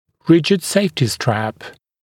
[‘rɪʤɪd ‘seɪftɪ stræp][‘риджид ‘сэйфти стрэп]жесткий предохранительный ремешок